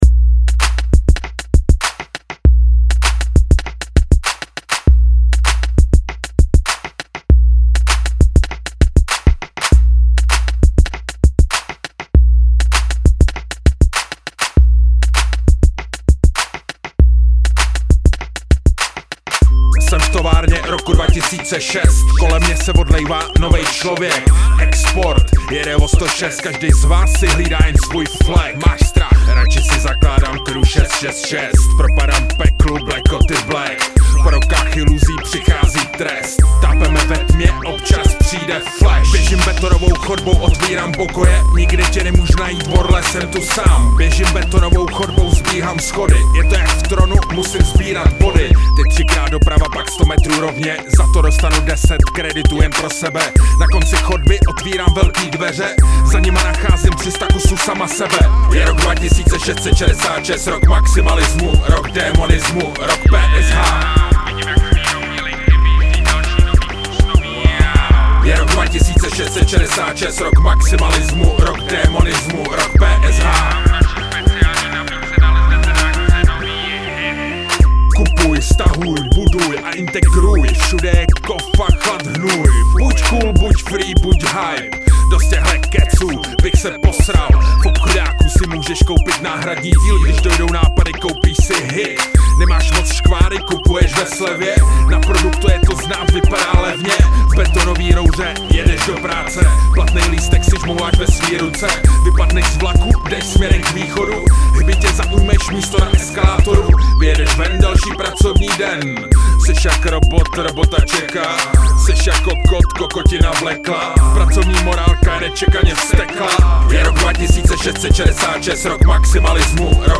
Styl: Hip-Hop